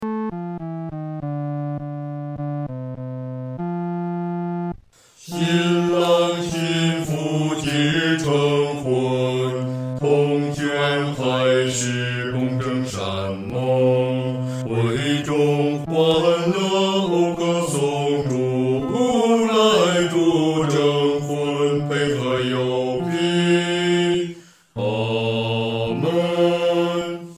男高
本首圣诗由网上圣诗班录制